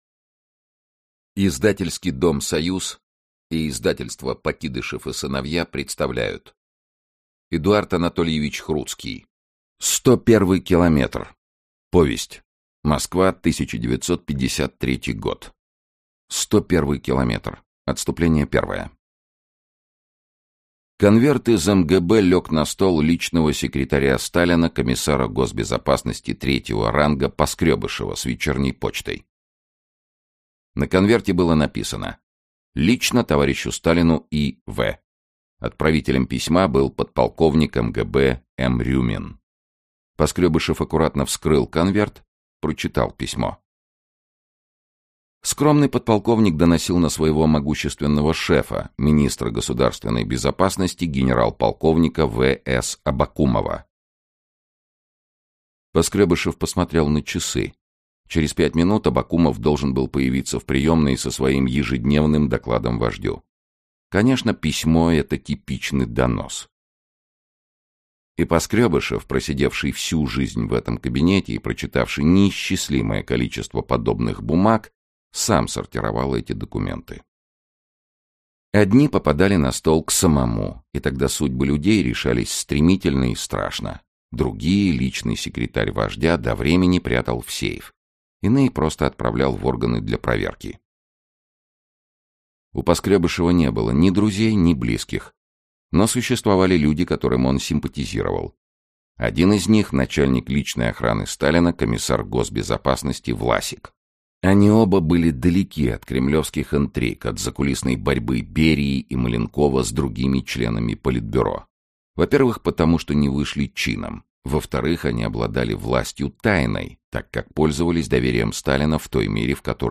Аудиокнига Сто первый километр | Библиотека аудиокниг